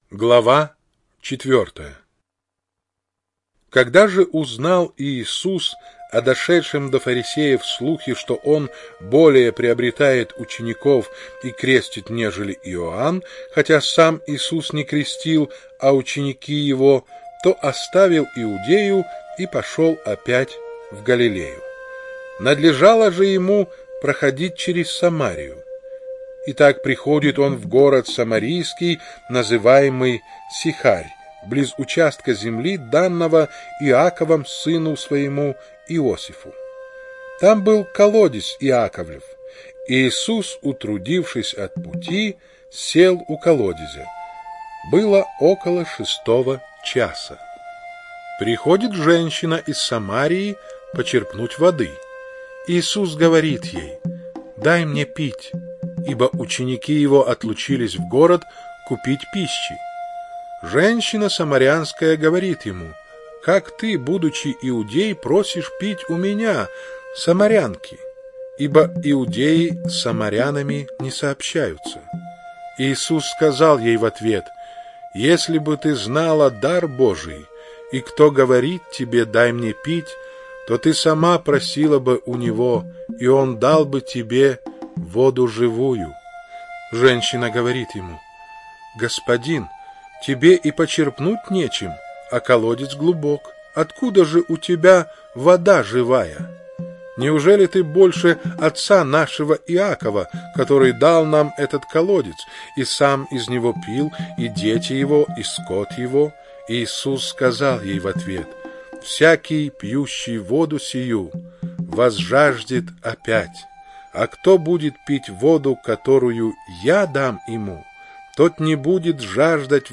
Чтение сопровождается оригинальной музыкой и стерео-эффектами